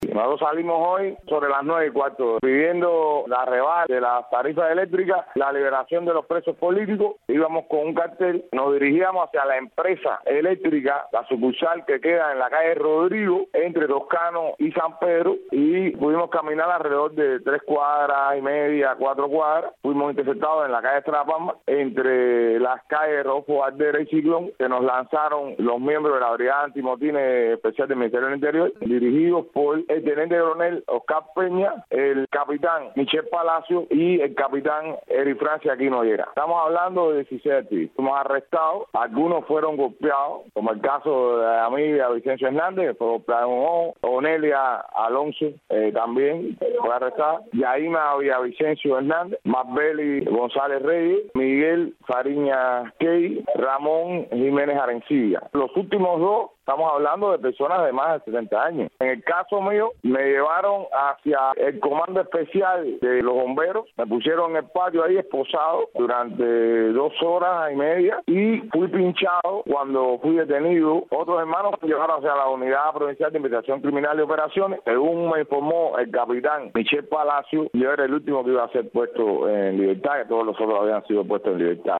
Declaraciones en Santa Clara